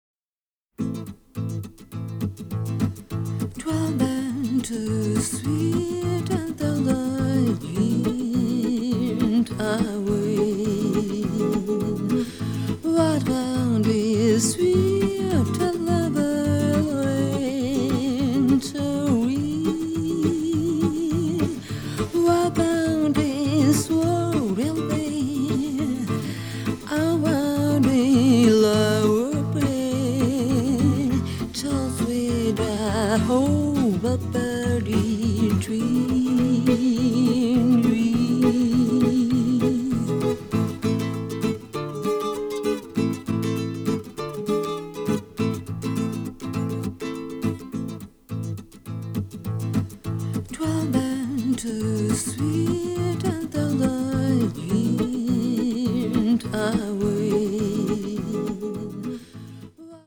giallo score
sophisticated avant-garde sound